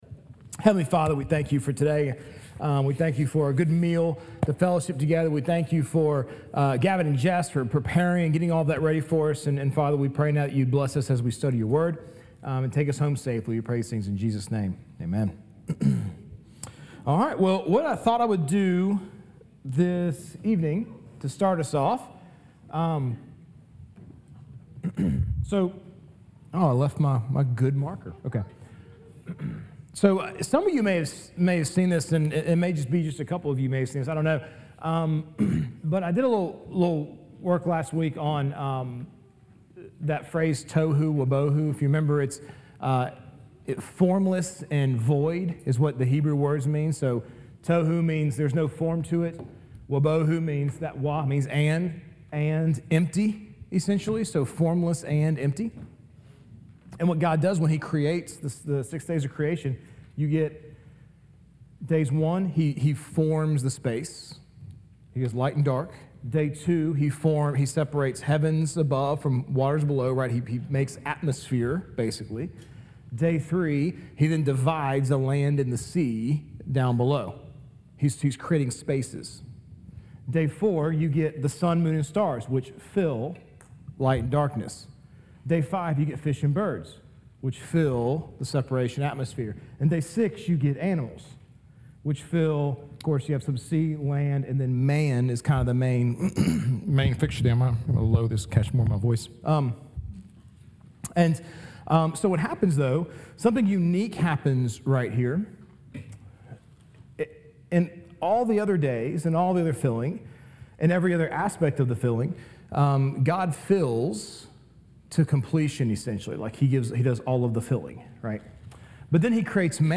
Sermons | Grace Fellowship (EPC)